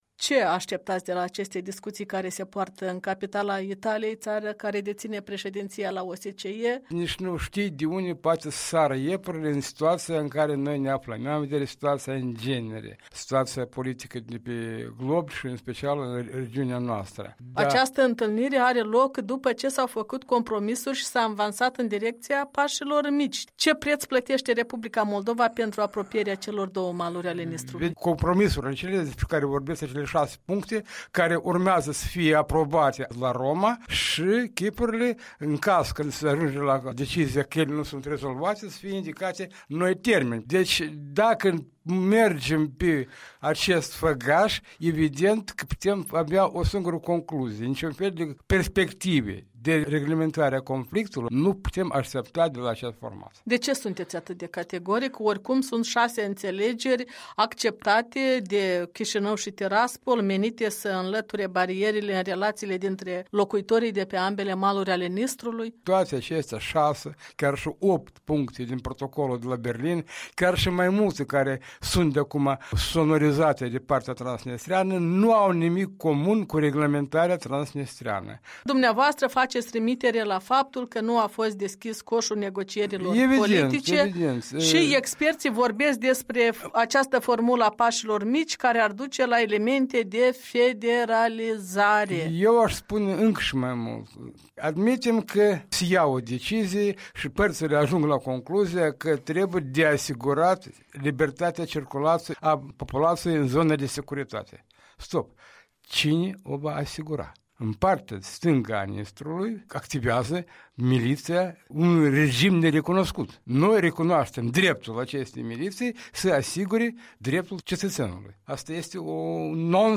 Ion Leahu, fostul membru al CEC, în dialog